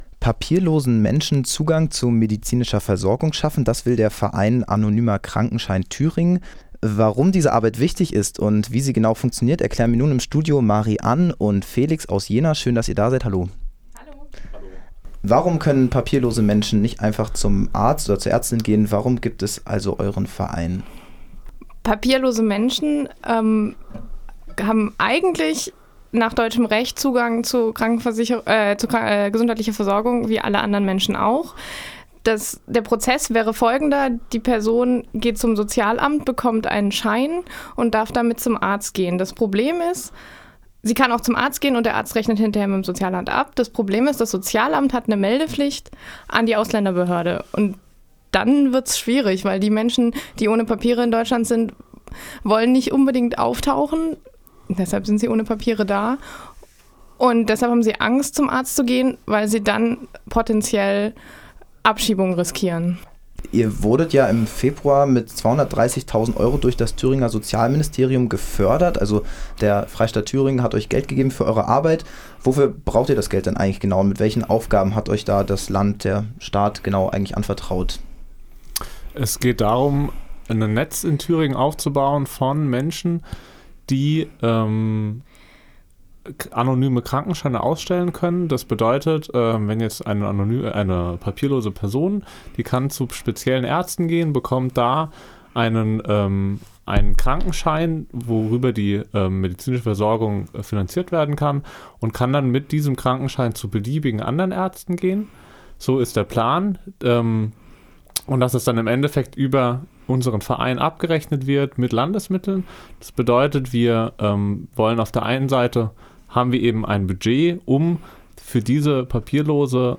Interview Anonymer Krankenschein.mp3